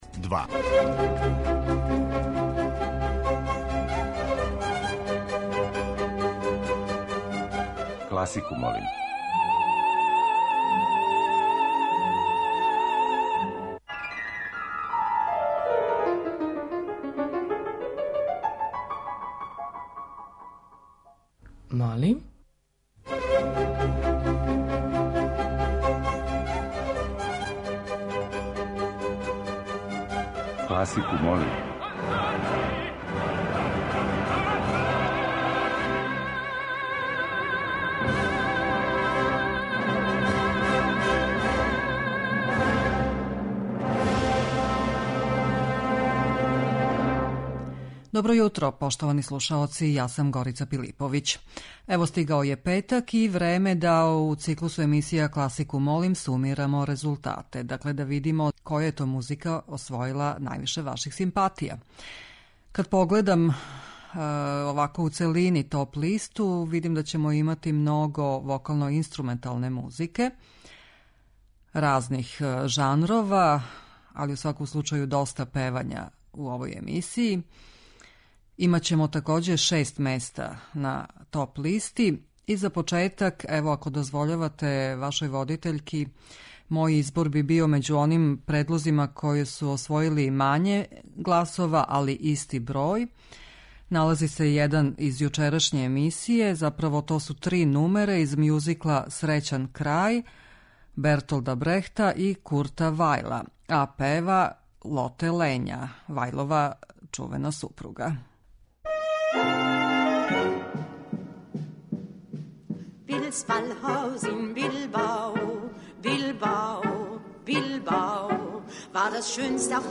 Недељна топ-листа класичне музике Радио Београда 2